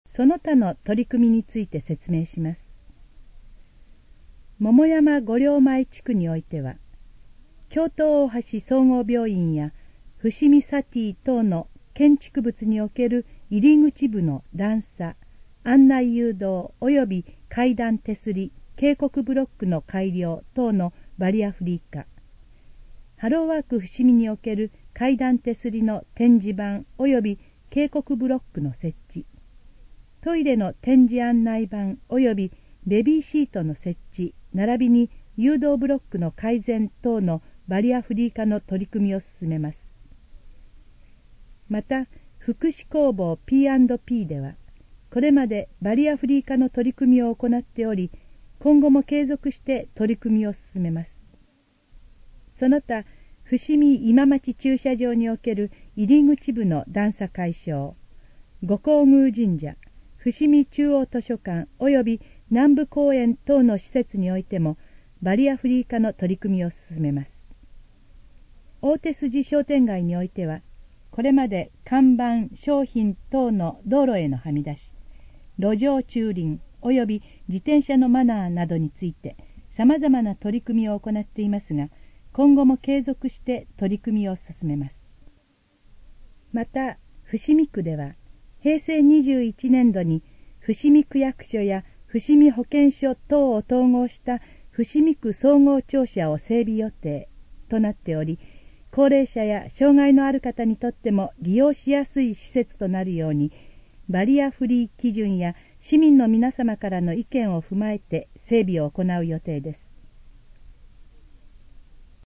以下の項目の要約を音声で読み上げます。